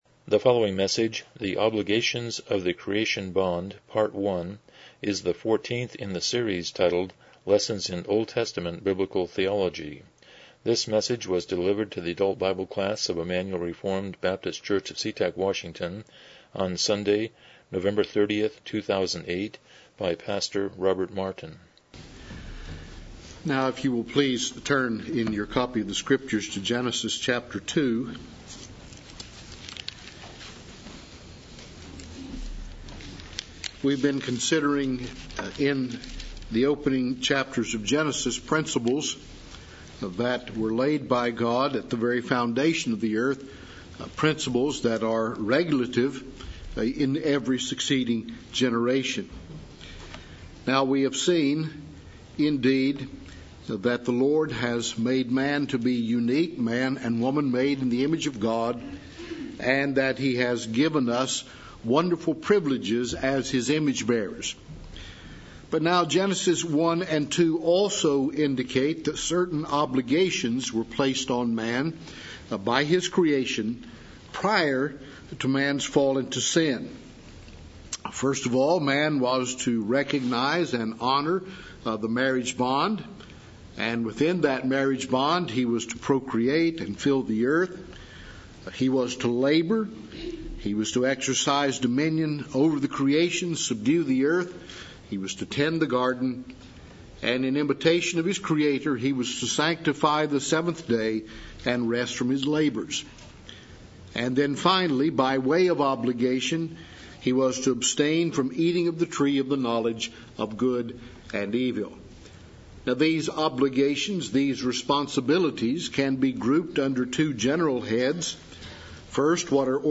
Series: Lessons in OT Biblical Theology Service Type: Sunday School